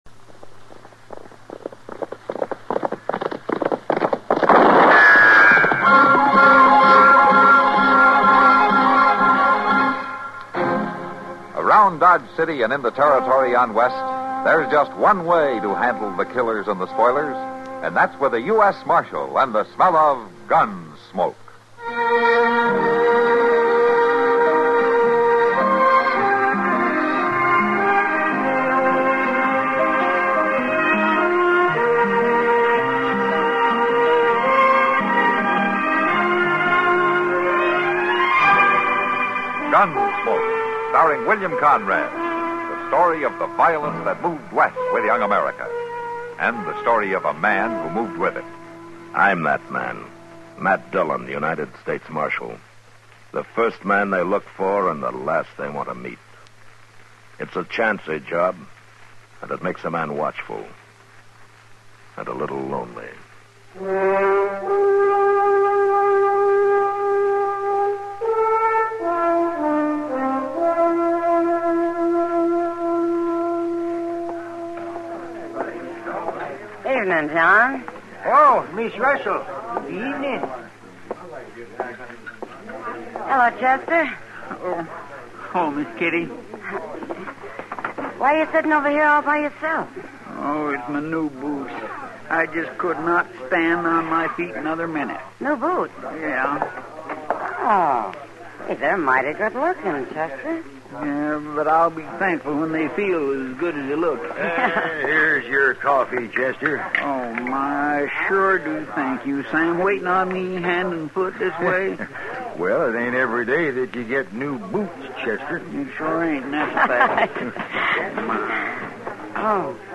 Gunsmoke is an American radio and television Western drama series created by director Norman Macdonnell and writer John Meston. The stories take place in and around Dodge City, Kansas, during the settlement of the American West. The central character is lawman Marshal Matt Dillon, played by William Conrad on radio and James Arness on television.